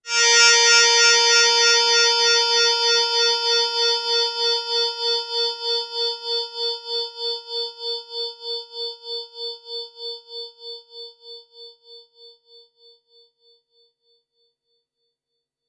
Korg Z1 Slow Piano " Korg Z1 Slow Piano A5 ( Slow Piano82127)
标签： MIDI-速度-63 ASharp5 MIDI音符-82 Korg的-Z1 合成器 单票据 multisam PLE
声道立体声